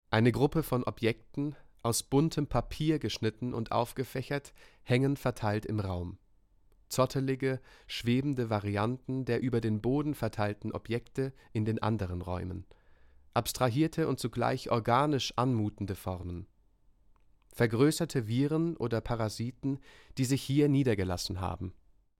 Audioguide zur Ausstellung im Kunstverein Aalen